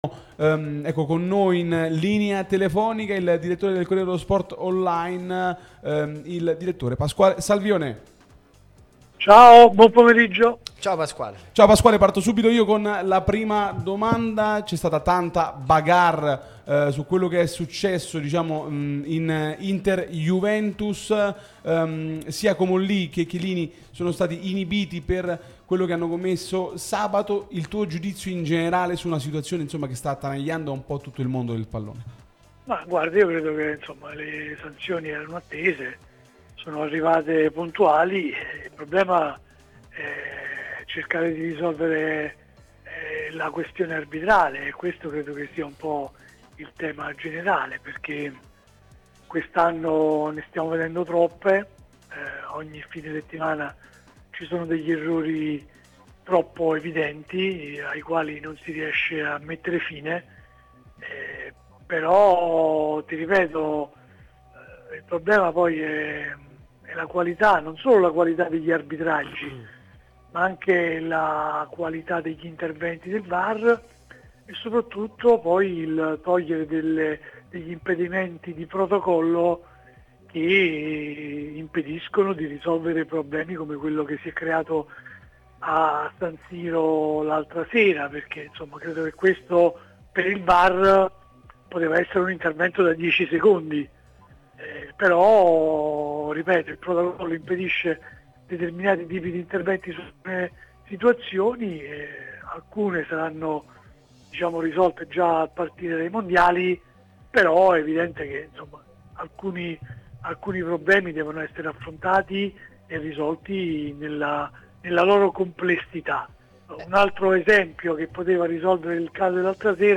sulla nostra Radio Tutto Napoli, prima radio tematica sul Napoli